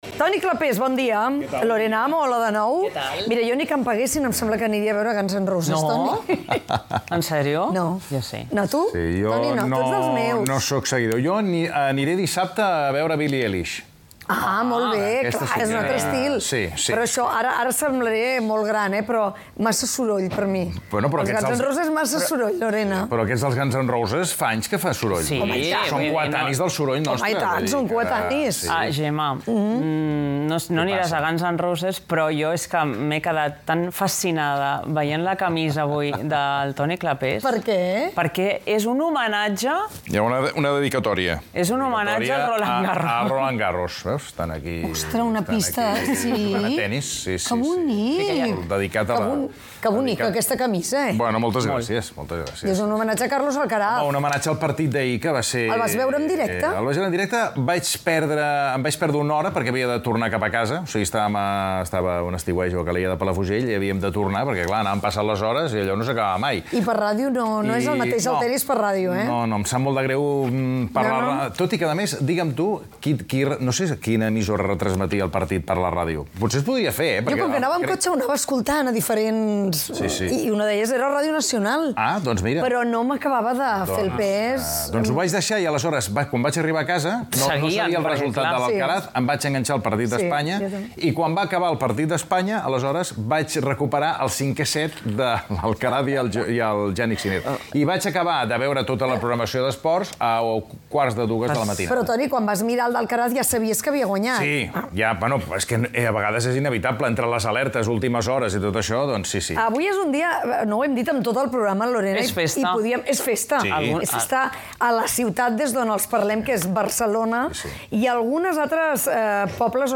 Diàleg inicial sobre el partit del tennista Carlos Alcaraz, treballar en dies festius, els viatges en avió i els equipatges, l'estiu a la platja